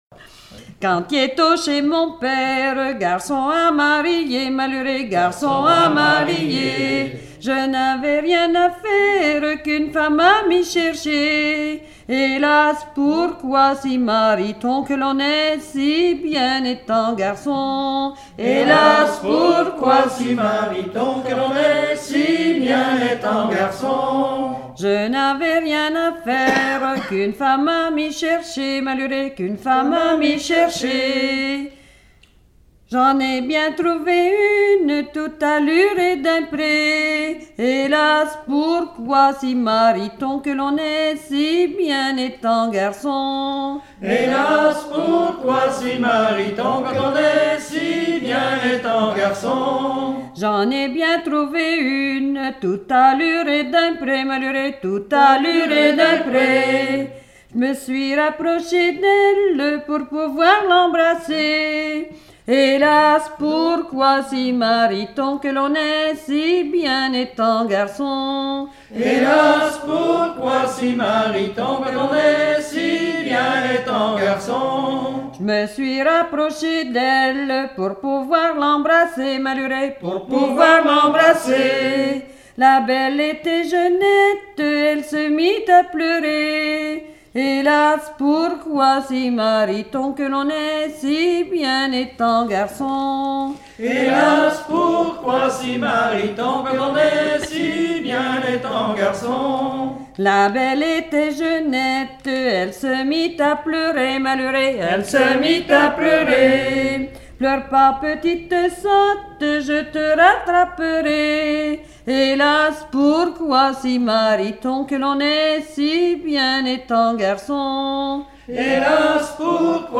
Genre laisse
Regroupement de chanteurs au Vasais
Pièce musicale inédite